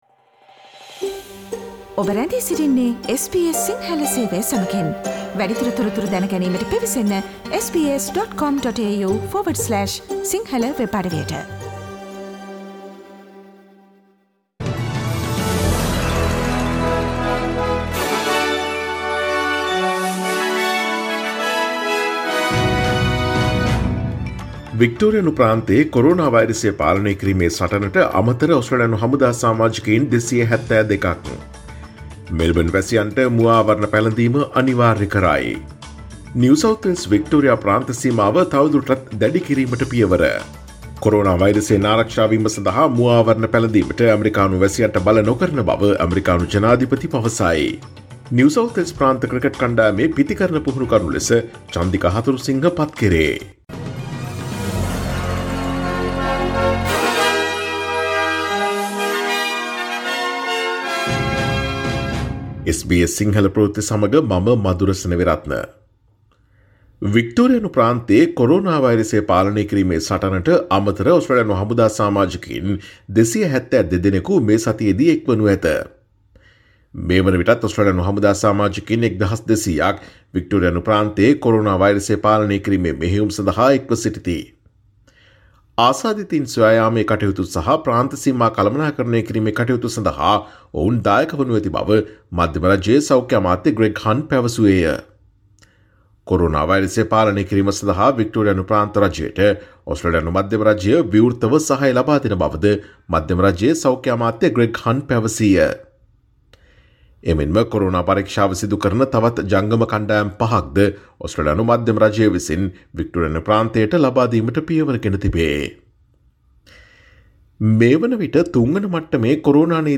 Daily News bulletin of SBS Sinhala Service: Monday 20 July 2020